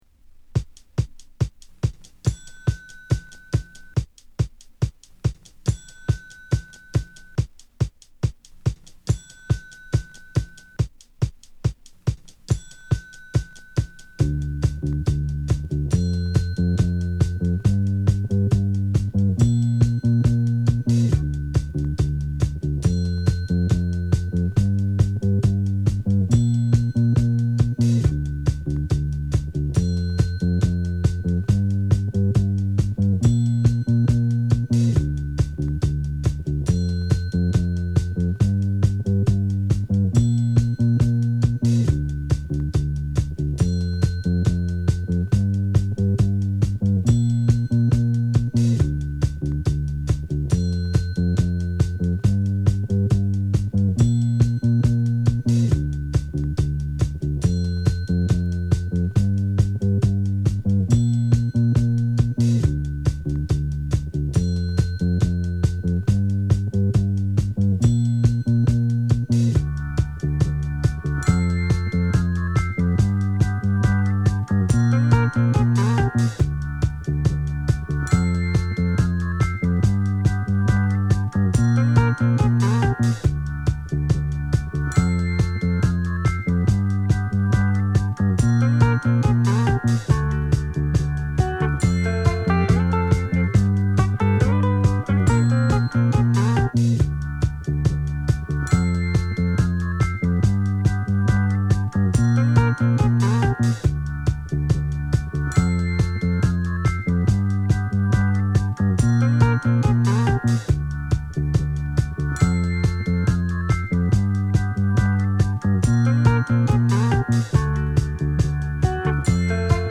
共に、それと気づかせぬ様な反復や展開再構築を散りばめた絶品エディットに！